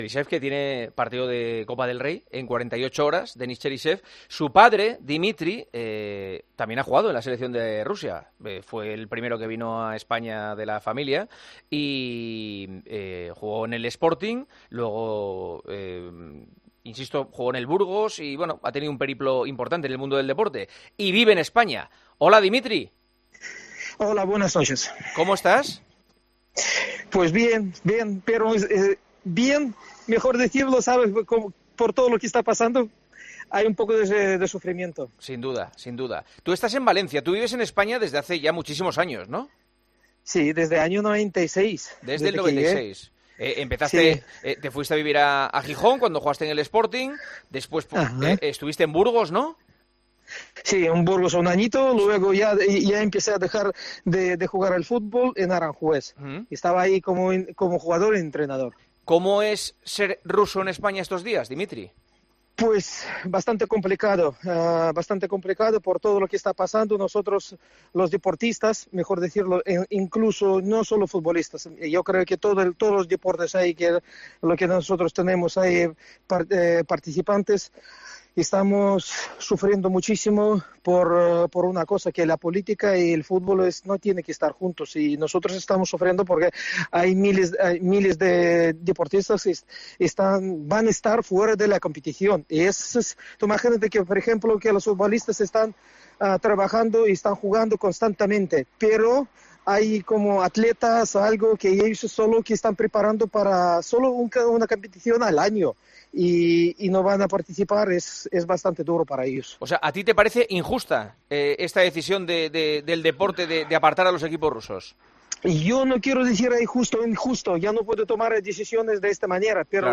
ENTREVISTA EN 'EL PARTIDAZO'